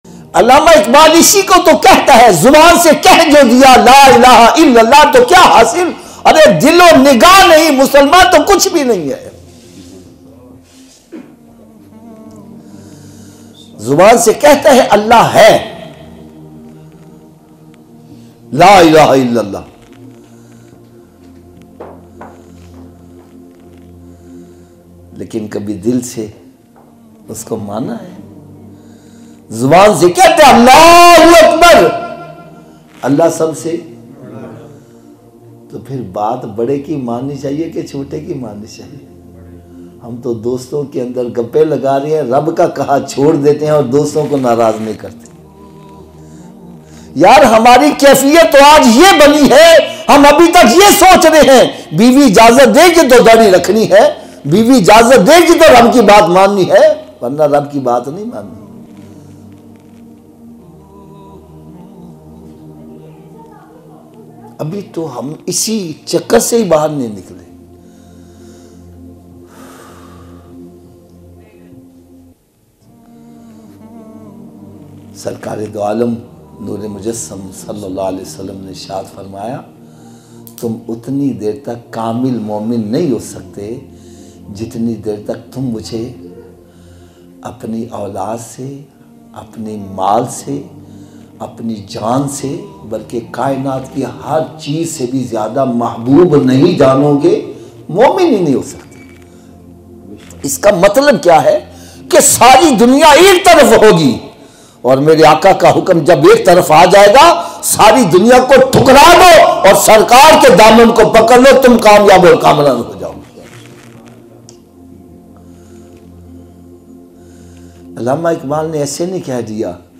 Very-Emotional-Bayan.mp3